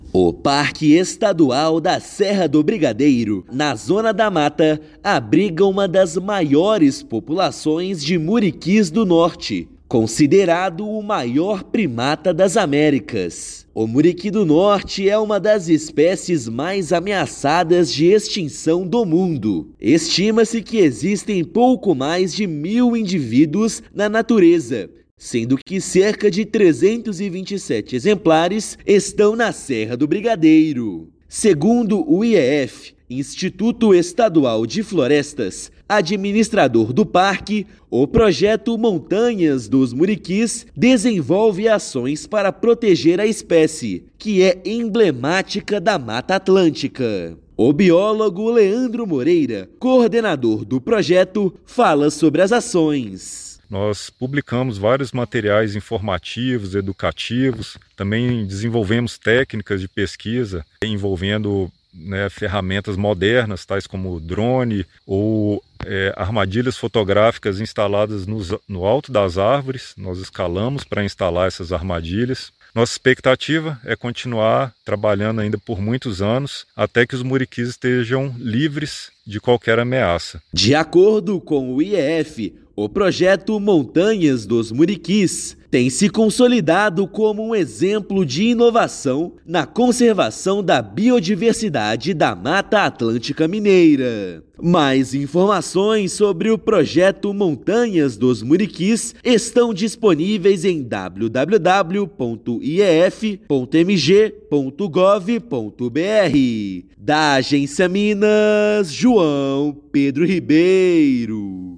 [RÁDIO] Projeto Montanhas dos Muriquis fortalece conservação de primatas ameaçados na Serra do Brigadeiro na Zona da Mata
Ações combinam ciência, tecnologia e educação ambiental para proteger uma das espécies mais ameaçadas do mundo. Ouça matéria de rádio.